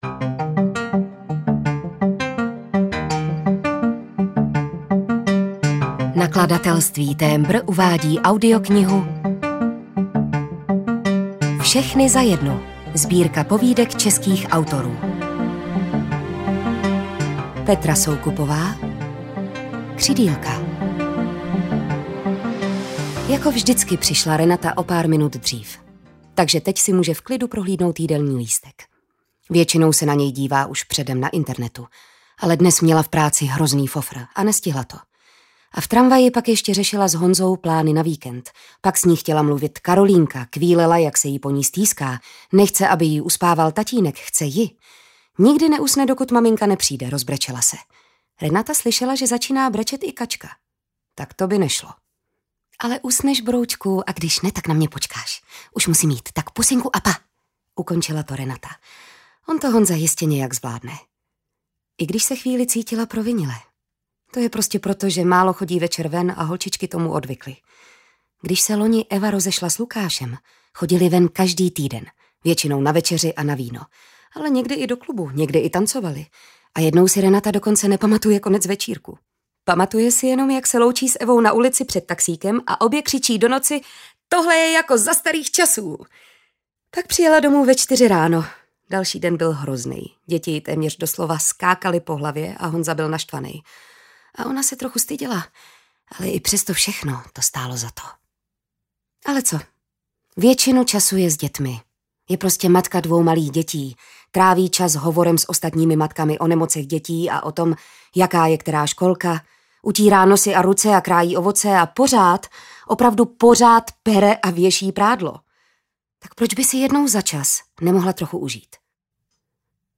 Všechny za jednu audiokniha
Ukázka z knihy